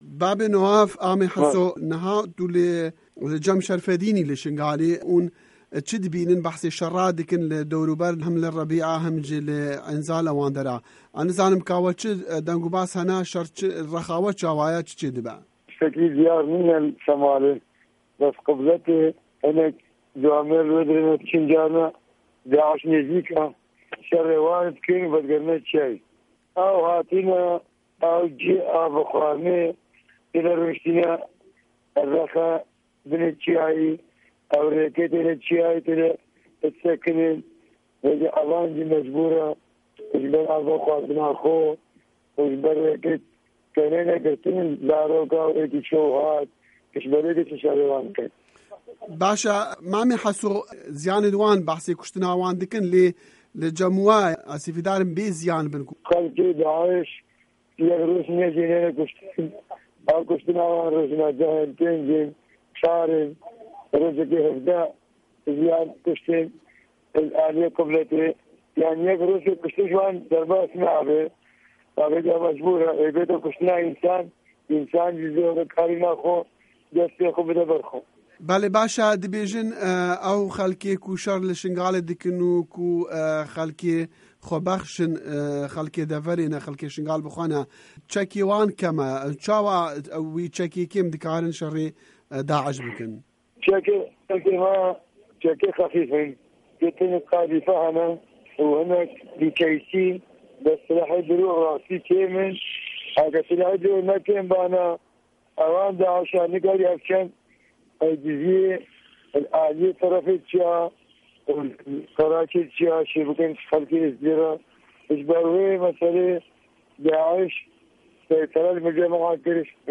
Di hevpeyvîneke taybet de hemwelatîyekî Êzîdî ku li Çîyayê Şengalê şerê DAÎŞ dike, behsa rewşa heyî dike.